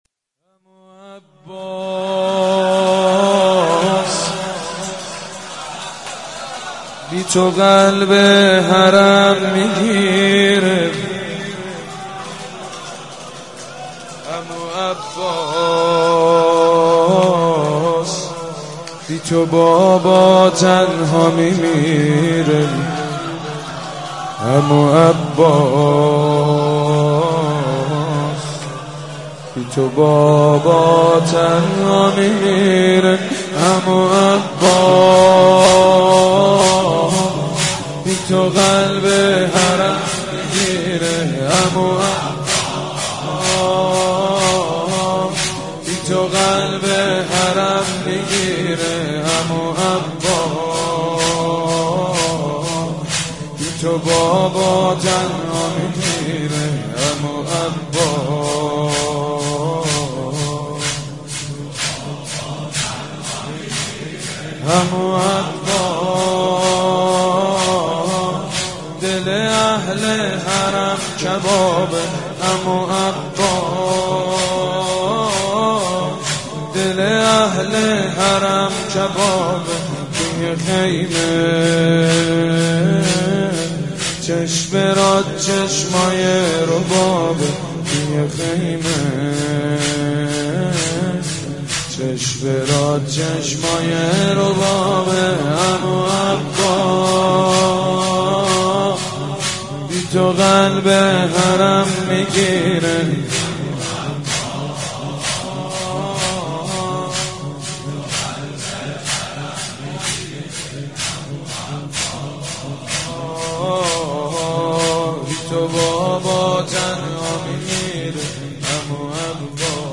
نوحه جديد بنی فاطمه, مداحی محرم بنی فاطمه